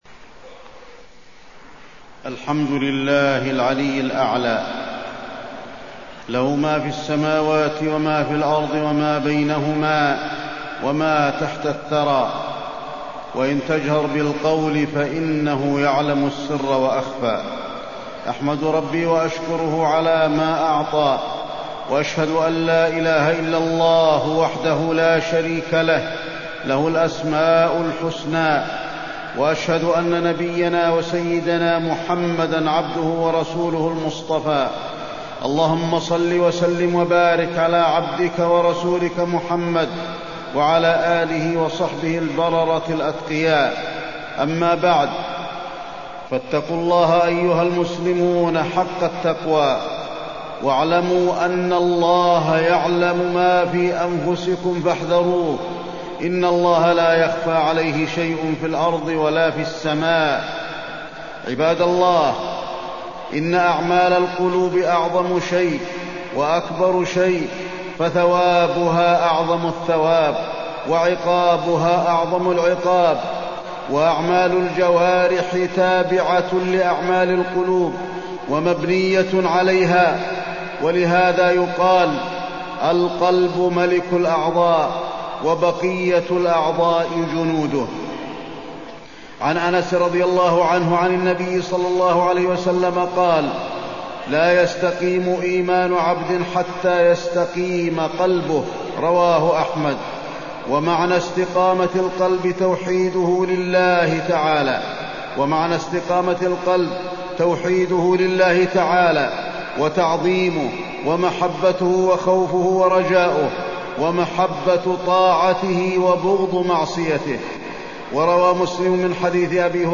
تاريخ النشر ٢٨ محرم ١٤٢٥ هـ المكان: المسجد النبوي الشيخ: فضيلة الشيخ د. علي بن عبدالرحمن الحذيفي فضيلة الشيخ د. علي بن عبدالرحمن الحذيفي الخوف والرجاء